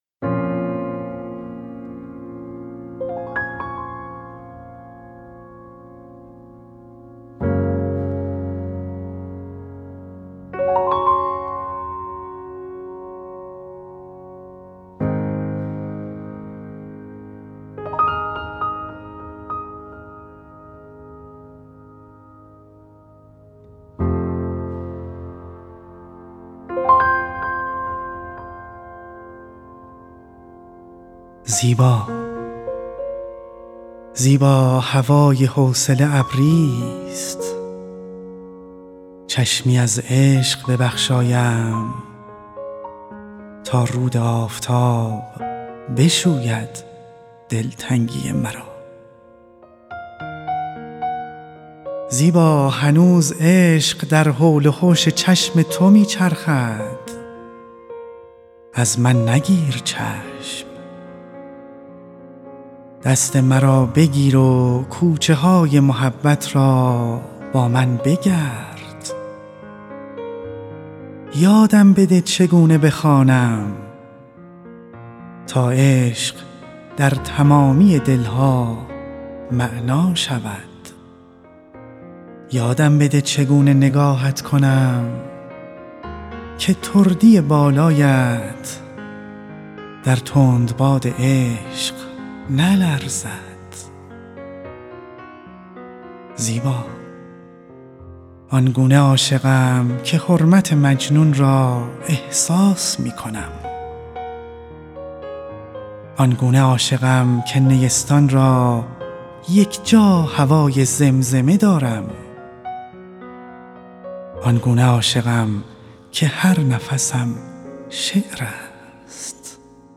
به گزارش خبرنگار فرهنگی خبرگزاری تسنیم، «پرواز خیال» مجموعه آثارِ صوتی است که با هدفِ مروری بر سروده‌های شعرای معاصر ایران و جهان تهیه و ضبط شده است. در این مجموعه برخی از سروده‌های مهم ادبیات ایران و جهان انتخاب و با همراهی موسیقی خوانده شده‌اند.